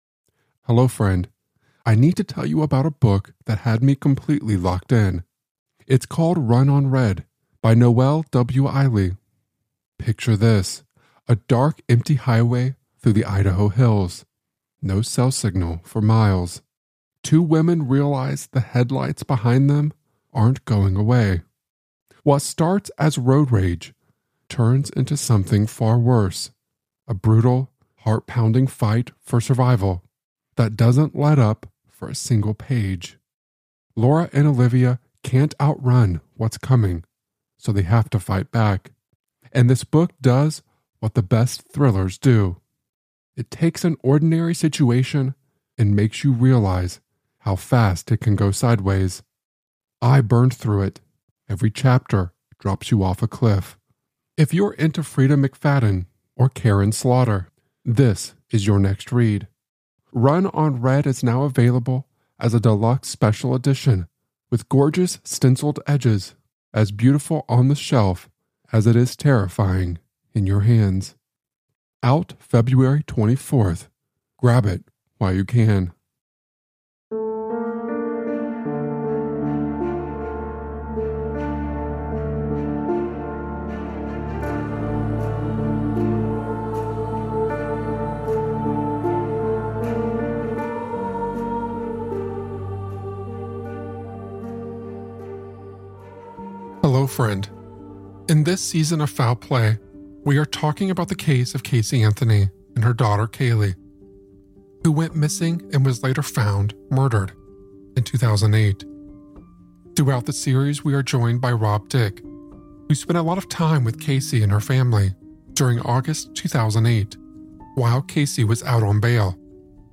Listeners will enter the Victorian courtrooms, visualizing the tension-laden trials through dramatizations and expert narratives.